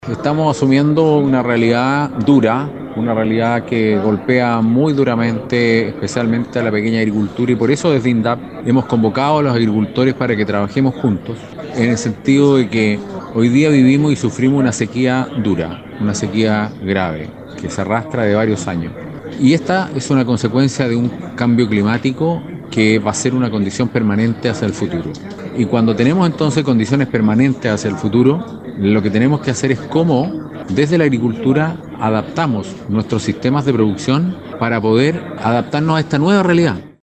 En el marco de un seminario que se repetirá en otros puntos del país, Recondo remarcó en Aconcagua, V Región, para Radio SAGO que, hay técnicas, tecnologías y nuevos métodos para enfrentar esta difícil condición, donde hay que ser más eficiente en el uso del recursos agua.